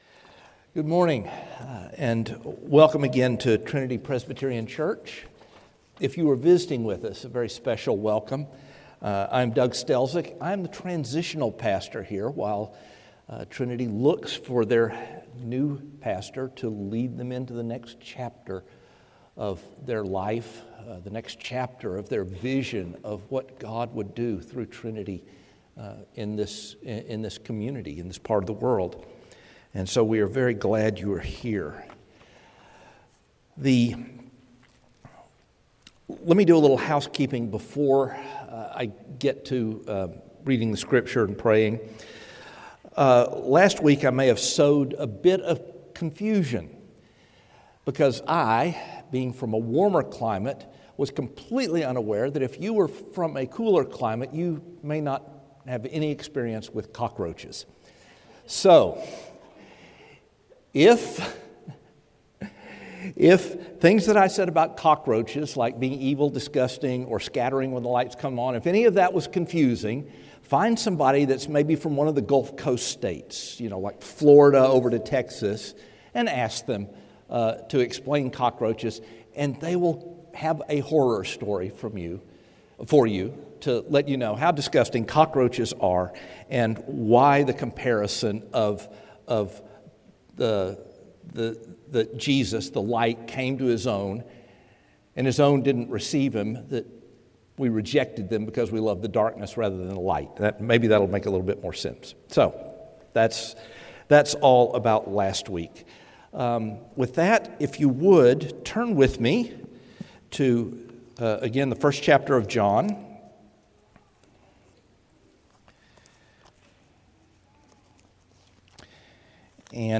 A message from the series "John: Who Is This?."